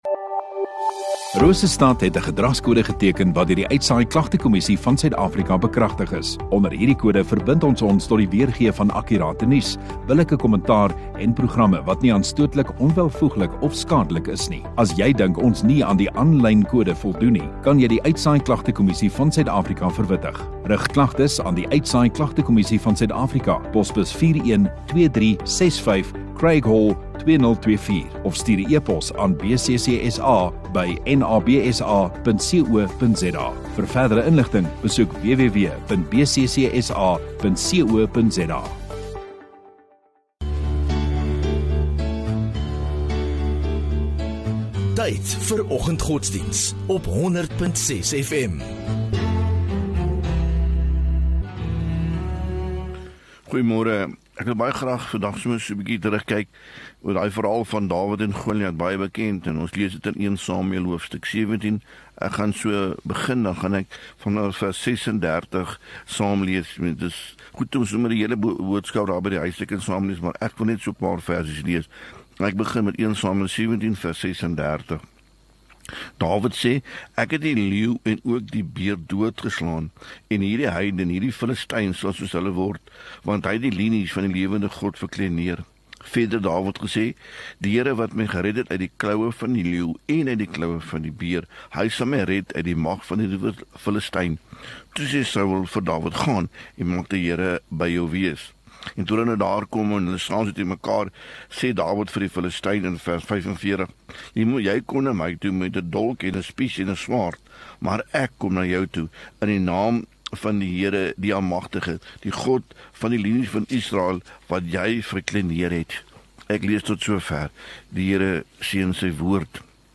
Rosestad Godsdiens